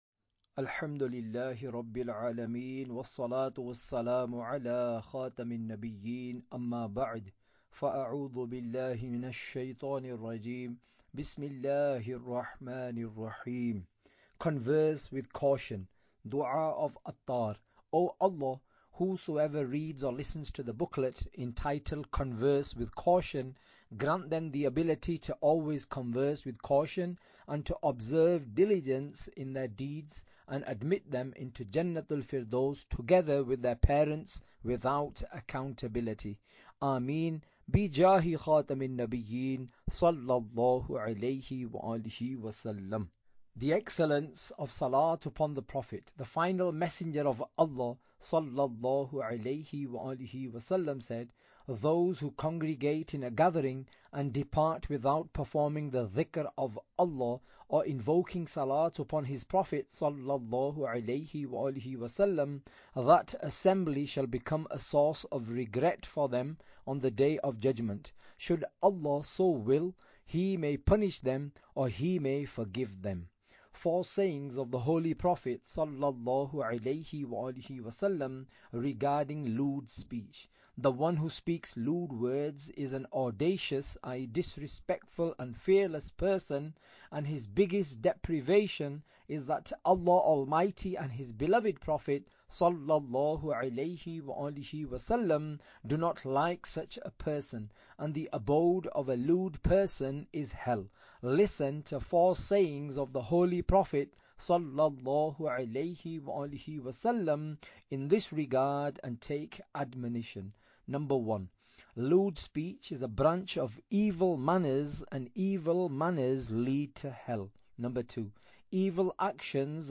Audiobook - Converse With Caution!